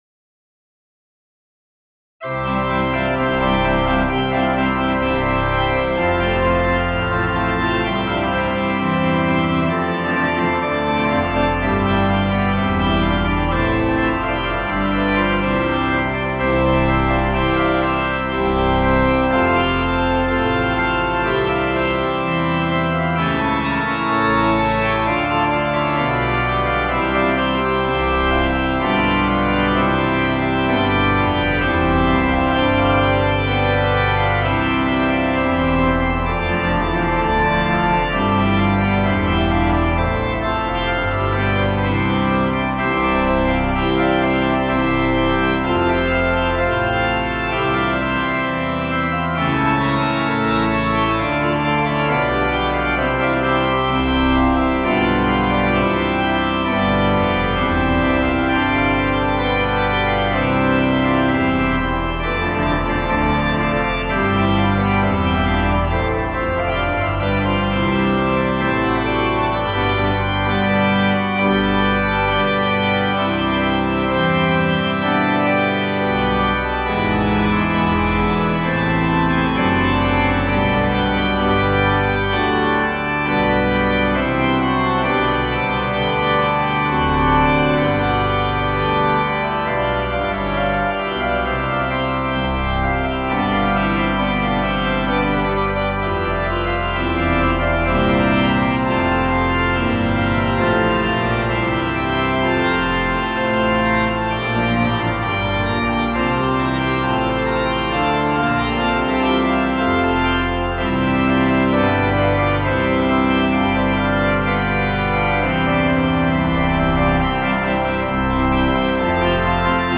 Testbericht – Organteq Kirchenorgel von Modartt
Für die Klangdateien wurden MIDI Files von Viscount Organs UK verwendet. Bei den Einstellungen wurde 1 Keyboard und All Stops gewählt.